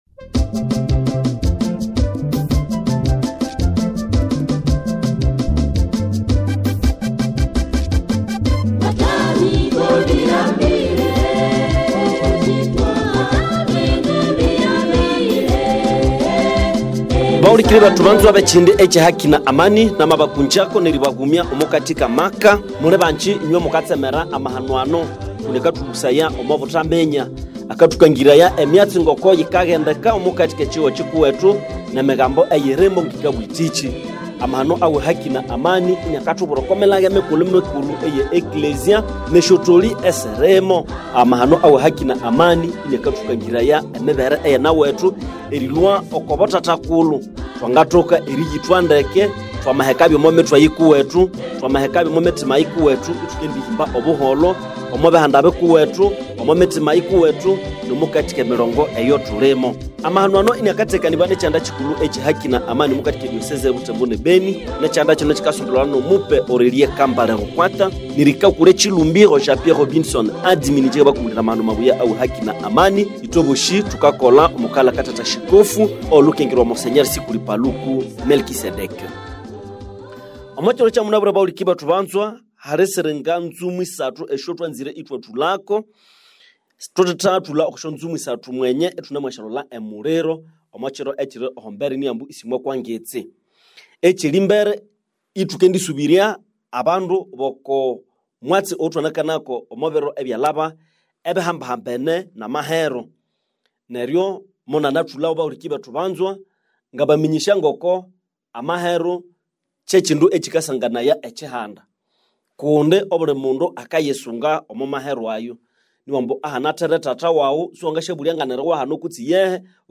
Émission Radio Écoutez l'émission ci-dessous